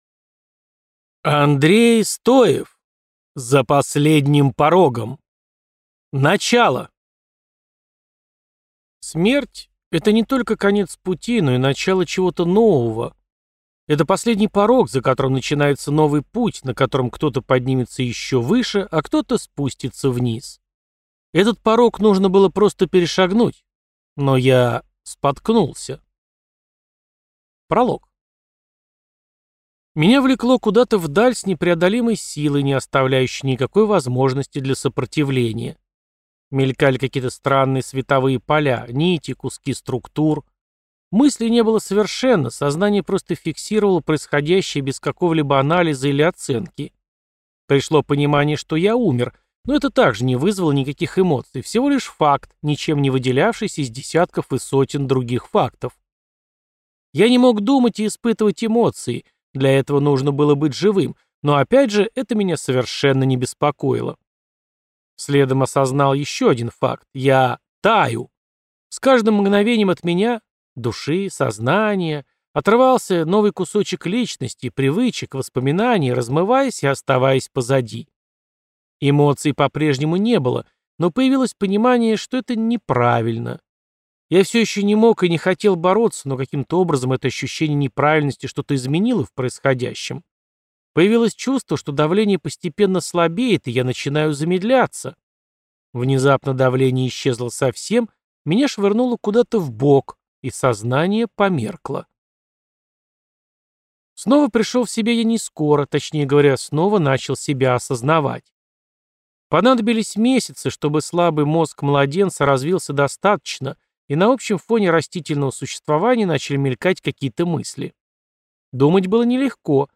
Аудиокнига За последним порогом. Начало | Библиотека аудиокниг